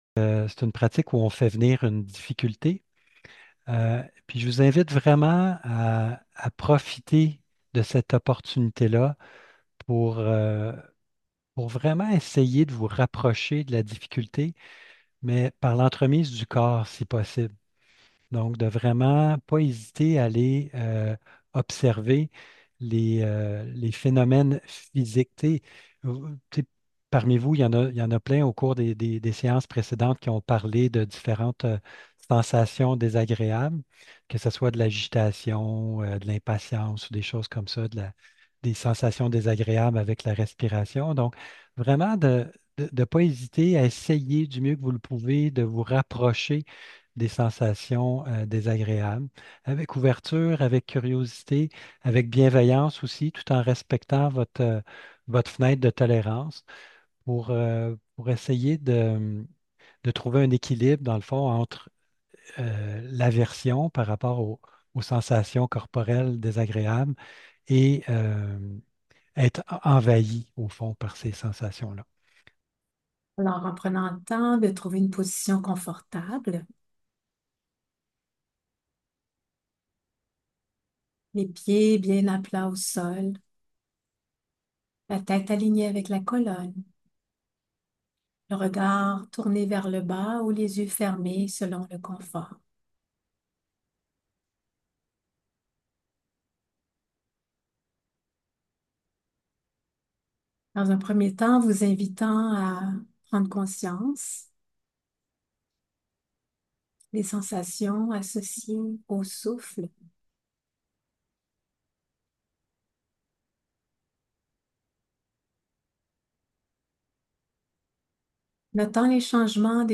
Méditation assise
S5-meditation-avec-une-difficultemp3-1.mp3